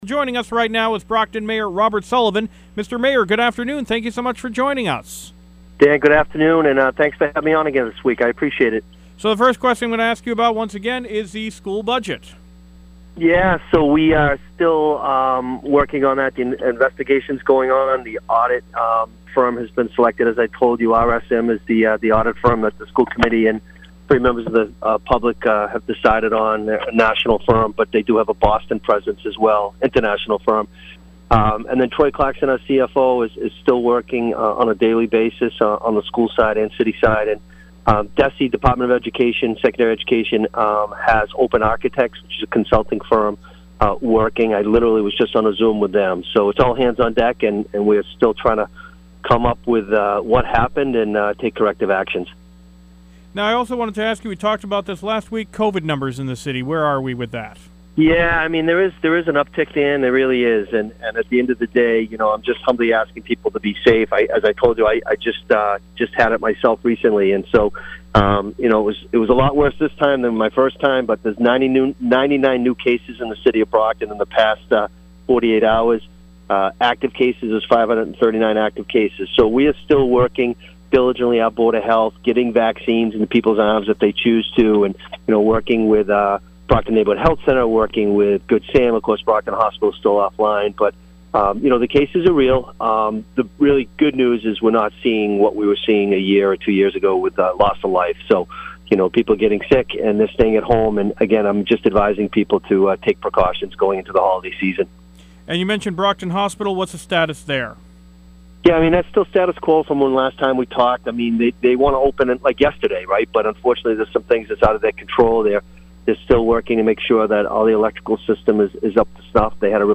Brockton Mayor Robert Sullivan speaks